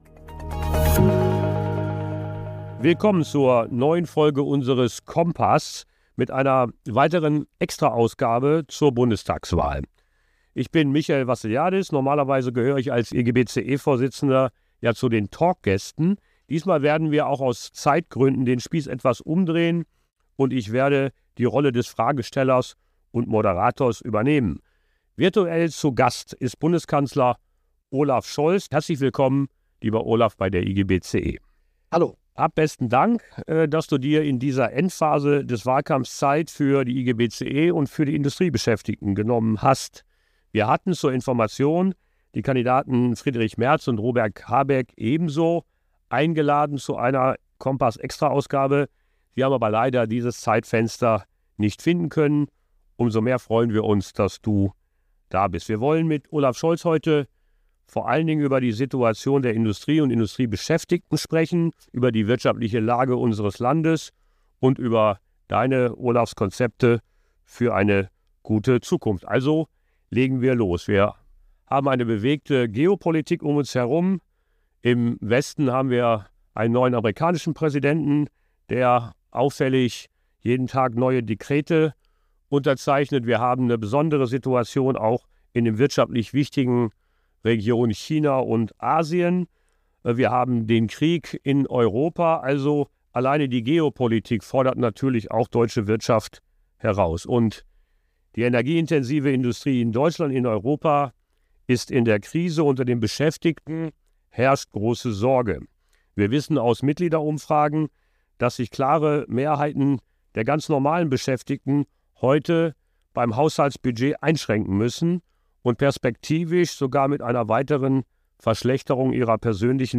Beschreibung vor 1 Jahr In einer neuen Sonderausgabe unseres „Kompass“-Talks befragt der IGBCE-Vorsitzende Michael Vassiliadis Noch-Bundeskanzler und SPD-Kanzlerkandidat Olaf Scholz zu dessen politischen Plänen nach der Bundestagswahl.